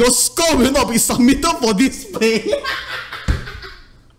failsound.wav